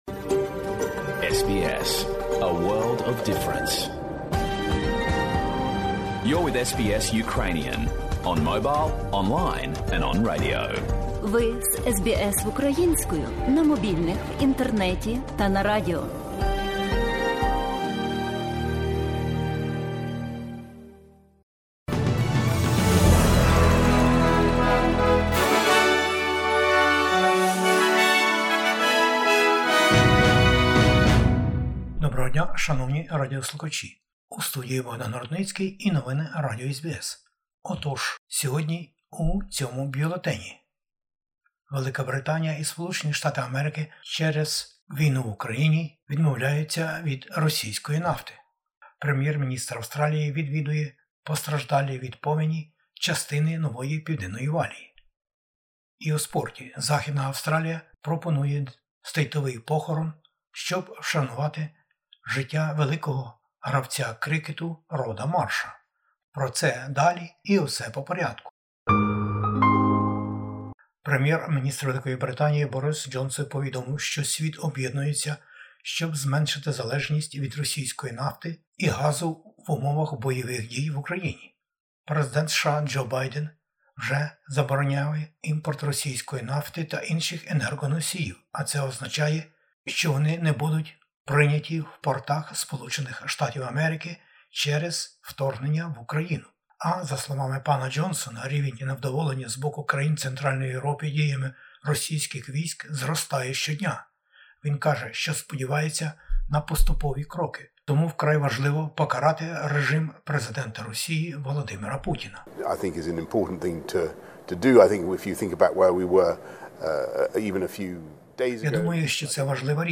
SBS новини українською - 9/03/2022
Про це і більше у бюлетені новин SBS Ukrainian…